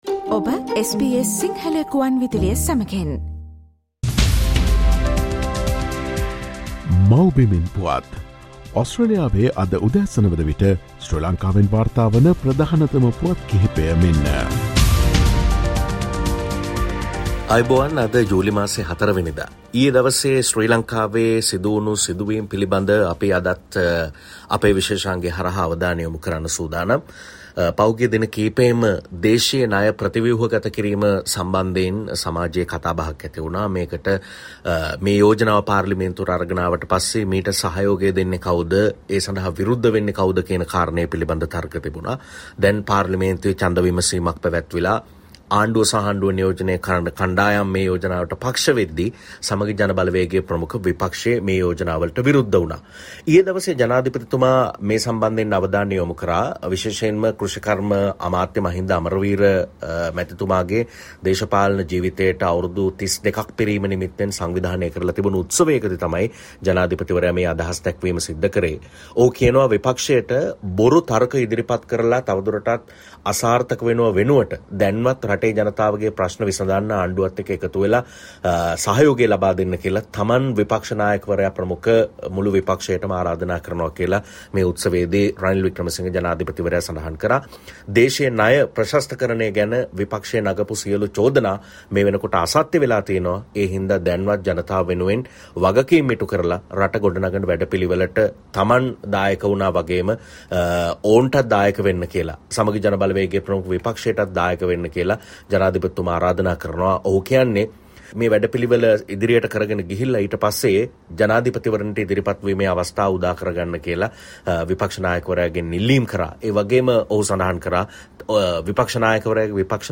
Sri Lankan News report on July 04 : Solve problems without doing false arguments, Ranil tells the opposition